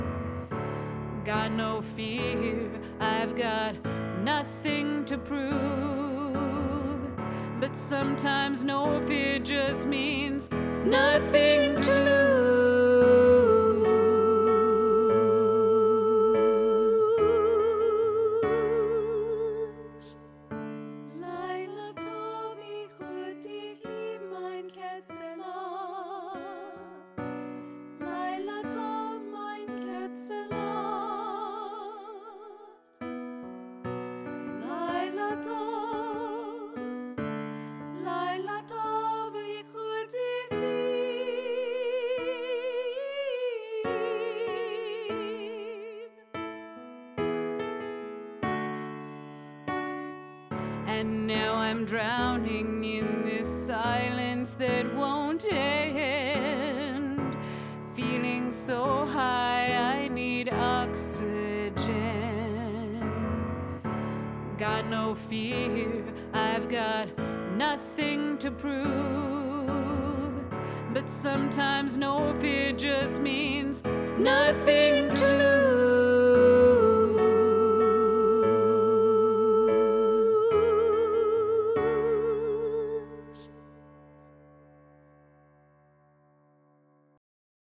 An Ancestral Lullaby